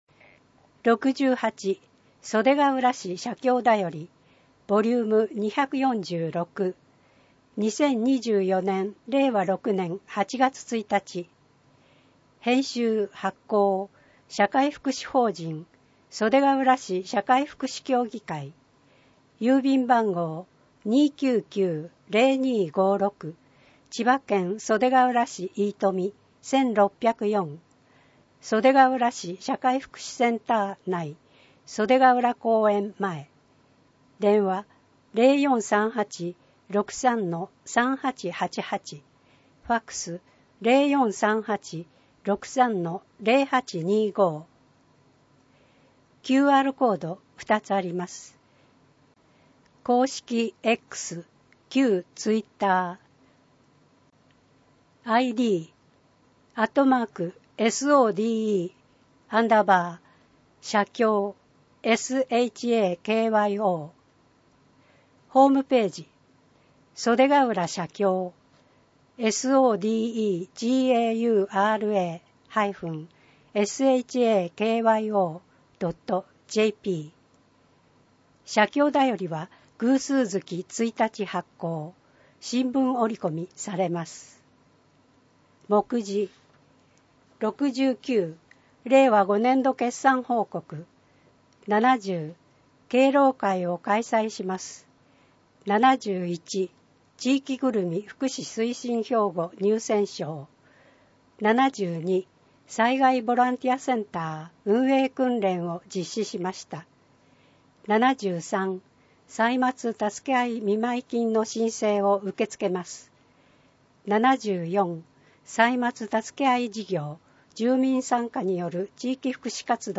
声の広報2024年8月｜社会福祉法人 袖ケ浦市社会福祉協議会
社会福祉法人 袖ケ浦市社会福祉協議会の声の広報2024年8月をご覧いただけます。